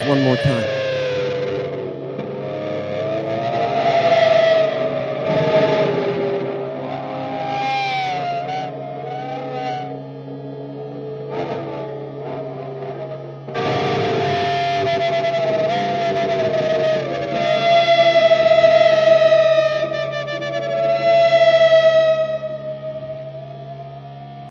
More electric guitar string bending very slowly through delay/reverb